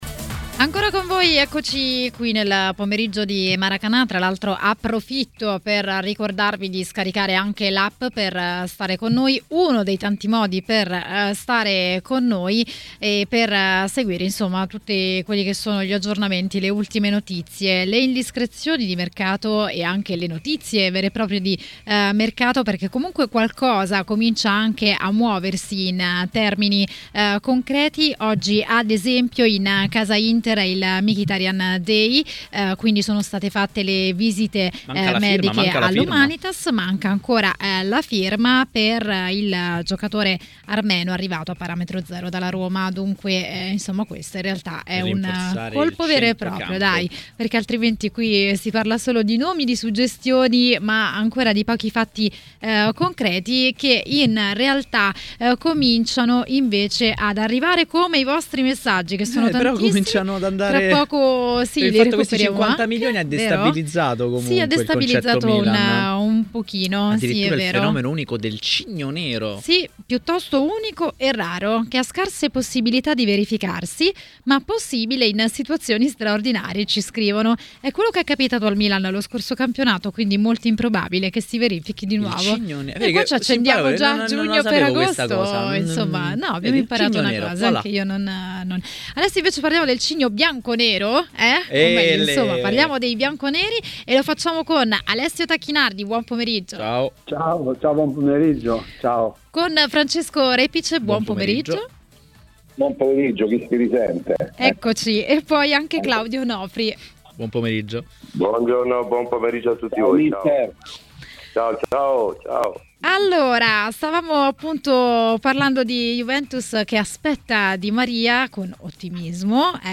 ospite di Maracanà, trasmissione di TMW Radio, ha parlato dei temi di mercato.